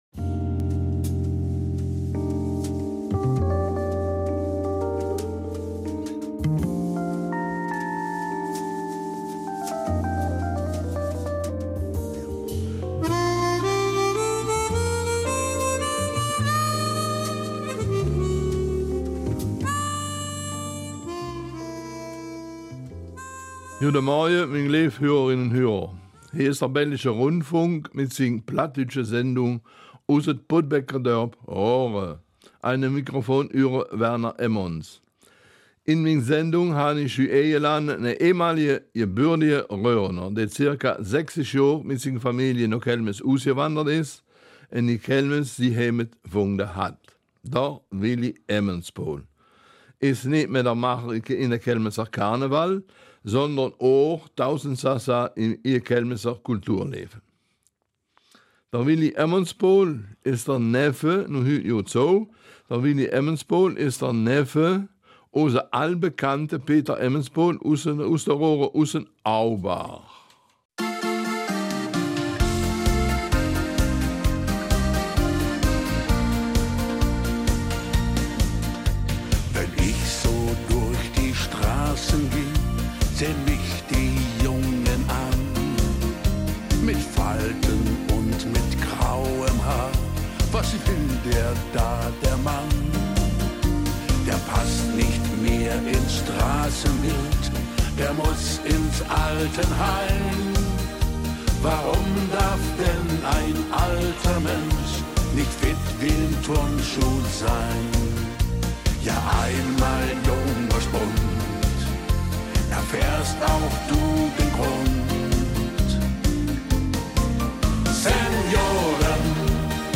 September 2019 Raerener Mundart Dein Browser unterstützt kein Audio-Element.